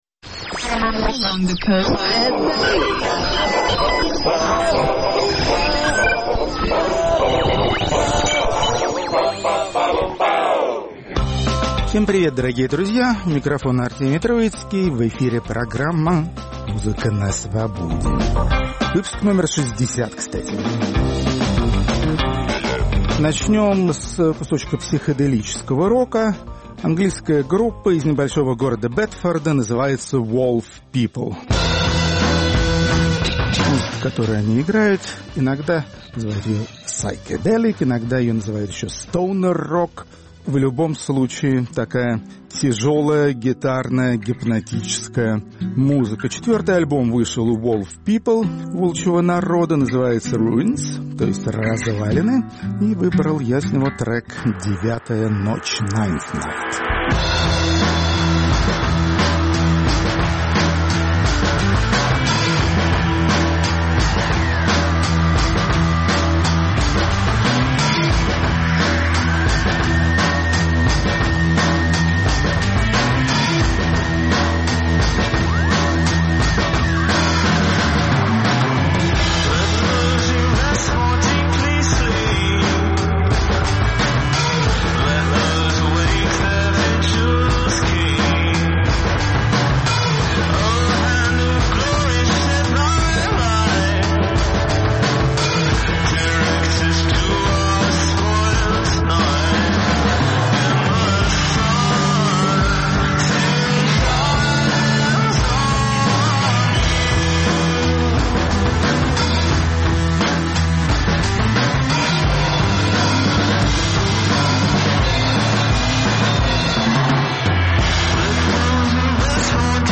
Шестидесятый выпуск программы "Музыка на Свободе" посвящен французской электронной музыке. Рок-критик Артемий Троицкий поднимает архивы, прослеживает динамику и знакомит с новинками.